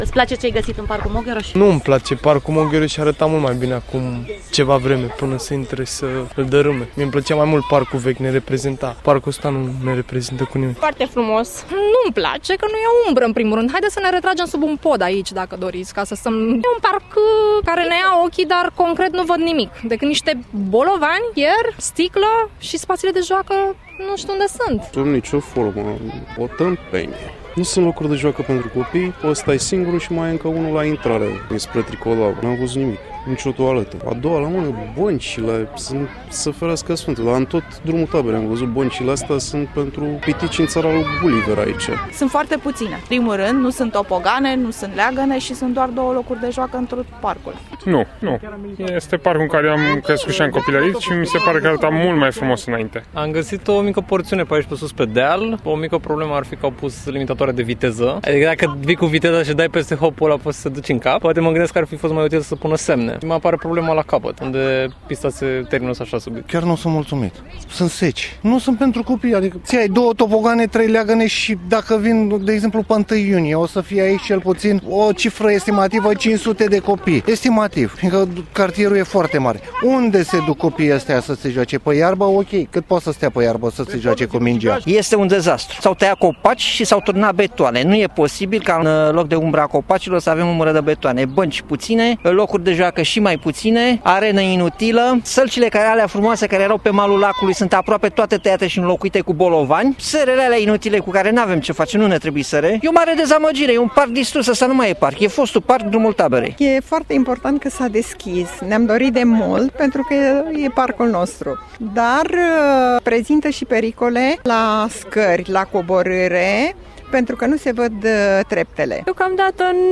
vox-oameni-parc-29-mai.mp3